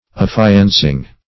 ([a^]f*f[imac]"an*s[i^]ng).]